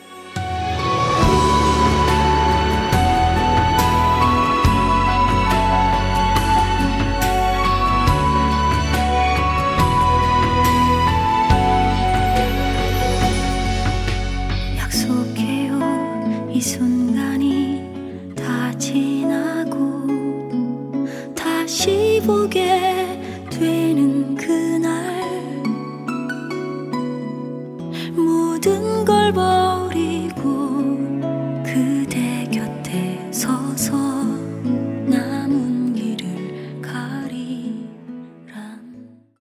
• Качество: 320, Stereo
грустные
струнные
красивый женский вокал
баллада